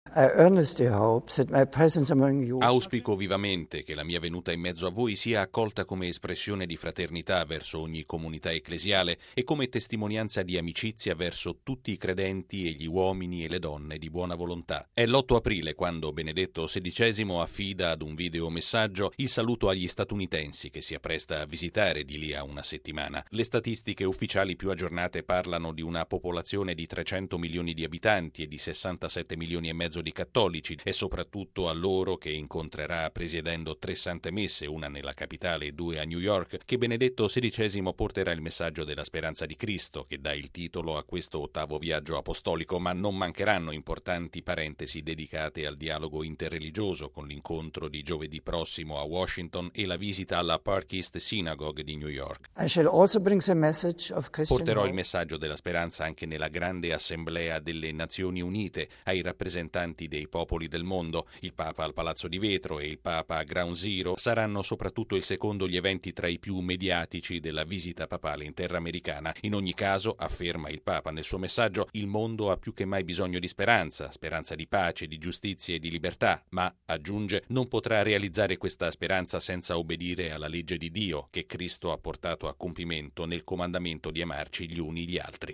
Vigilia della partenza di Benedetto XVI per gli Stati Uniti. Intervista con l'arcivescovo di Washington, Donald Wuerl: il Papa annuncerà a tutti la speranza del Cristo risorto